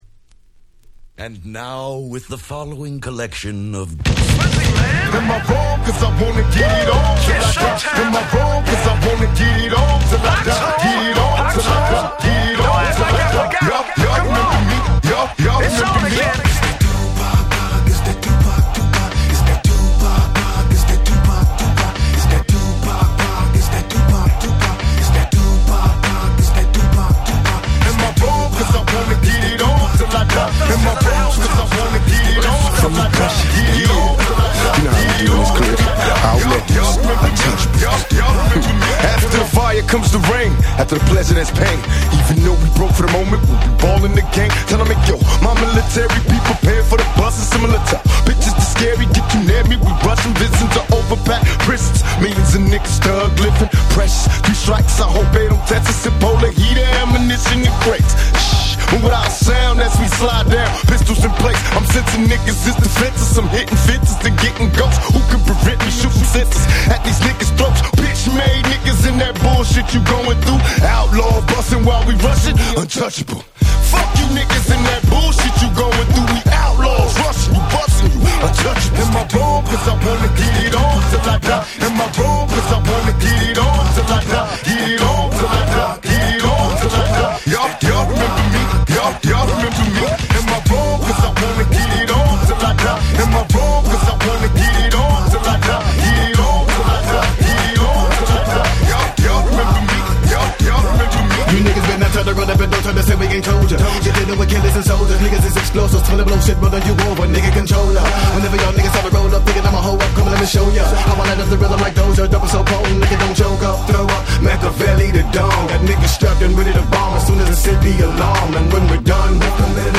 06' Smash Hit Hip Hop !!
Club Mix - Dirty
West ウエッサイ